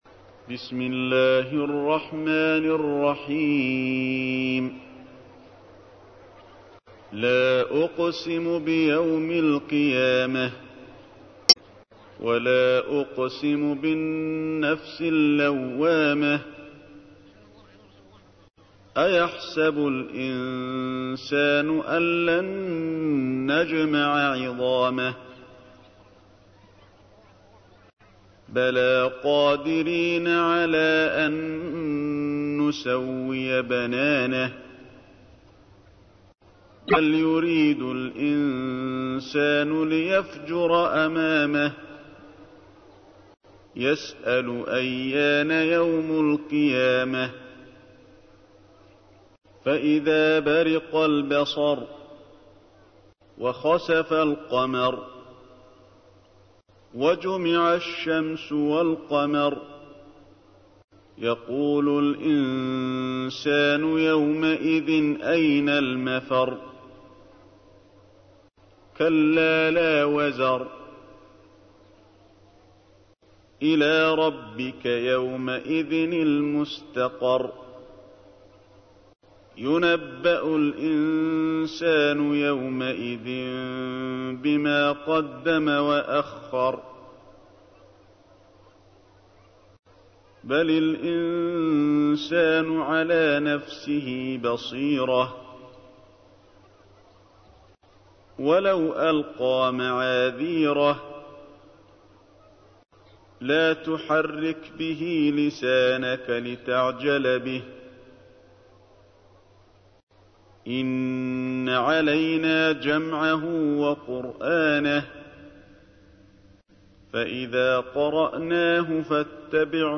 تحميل : 75. سورة القيامة / القارئ علي الحذيفي / القرآن الكريم / موقع يا حسين